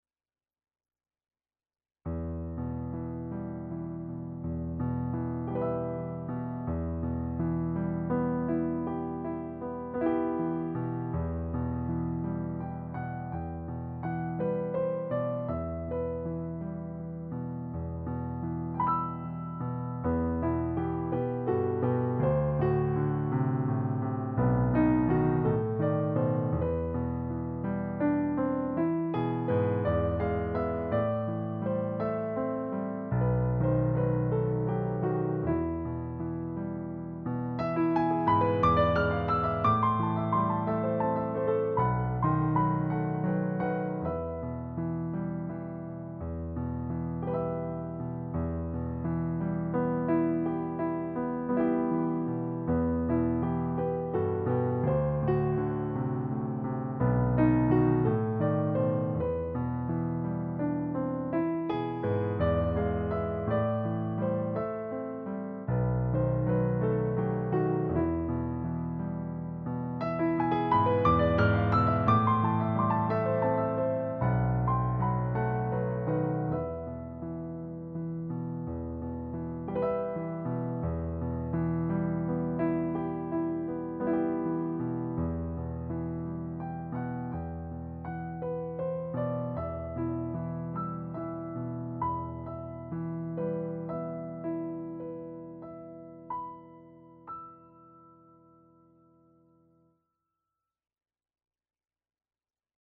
Segue nesse novo projeto, a gravação audiovisual dos arranjo para as 17 músicas do Cordão, para canto coral e piano.
Piano s/ Clique